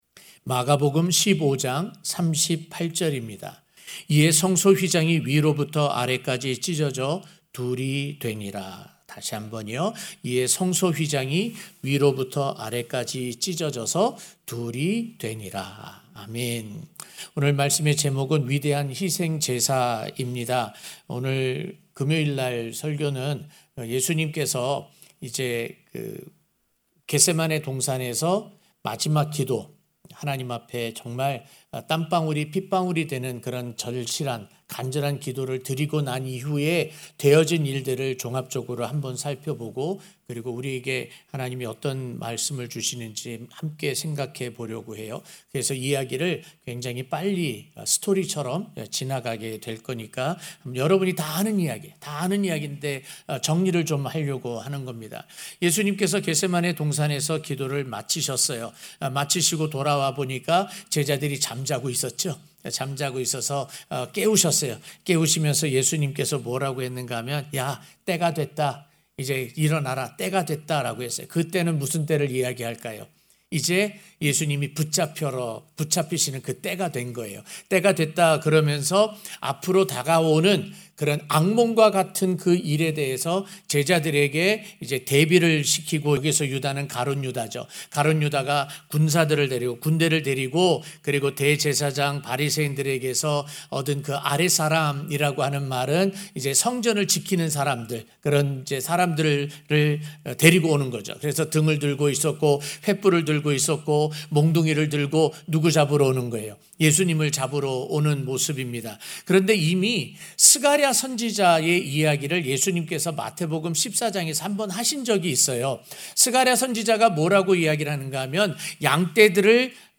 성금요일저녁예배-위대한 희생제사 (막 15:38)